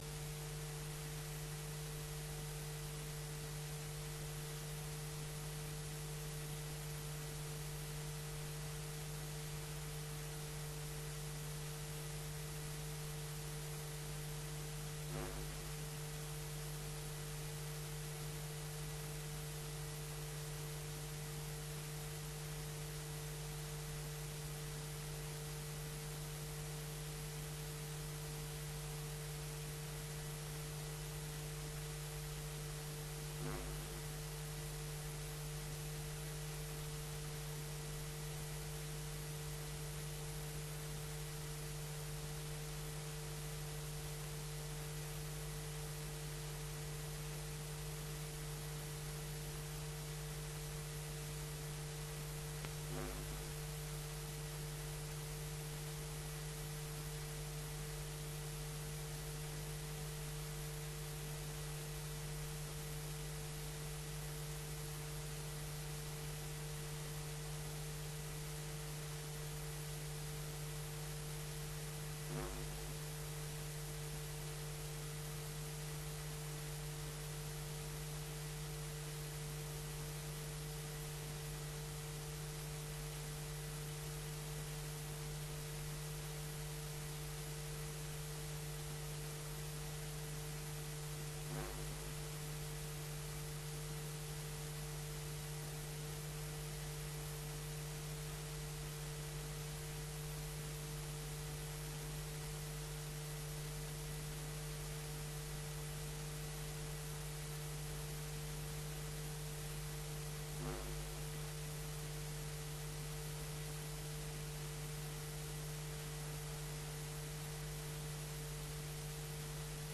Raadsvergadering 28 januari 2021 20:00:00, Gemeente Ouder-Amstel